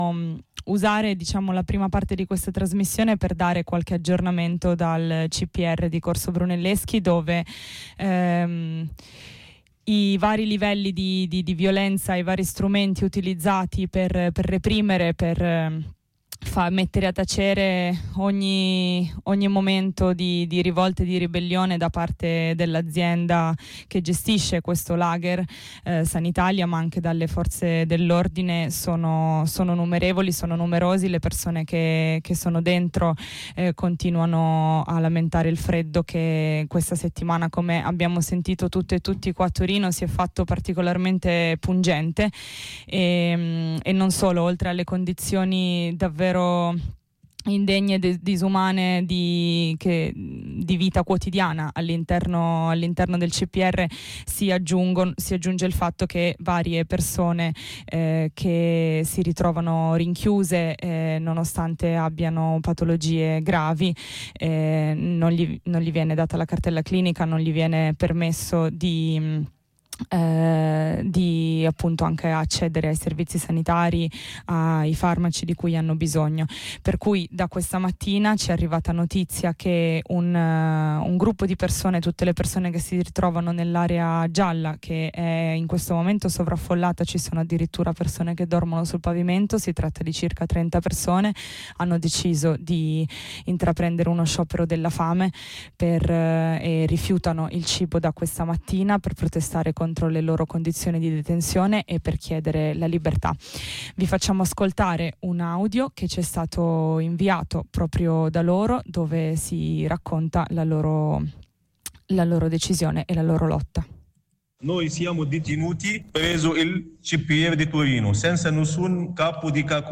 Qui di seguito un aggiornamento radiofonico, con il contributo audio dei detenuti in sciopero della fame: